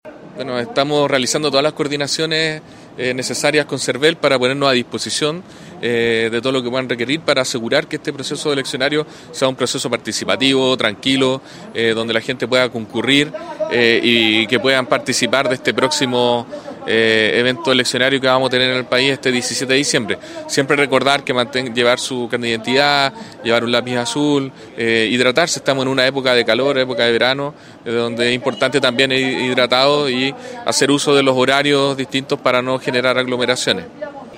COORDINACION-PLEBISCITO-Galo-Luna-Delegado-Presidencial-Regional.mp3